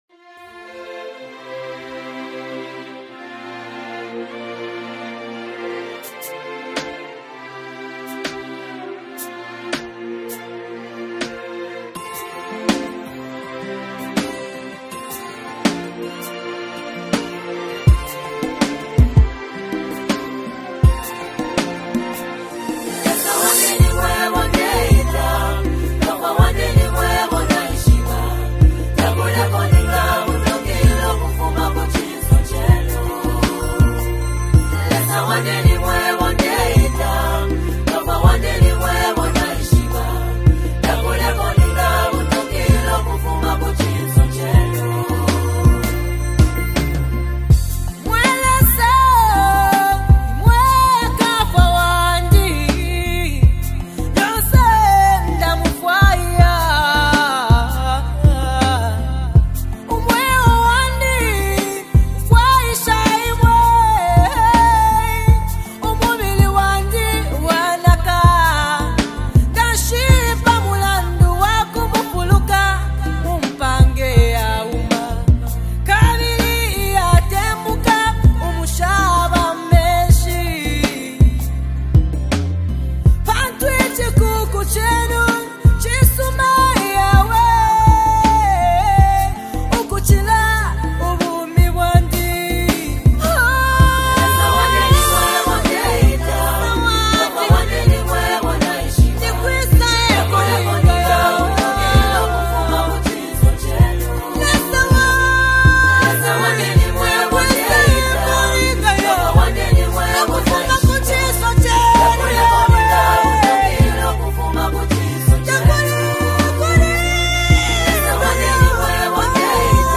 Best Classic Worship Song
Through soulful melodies, touching lyrics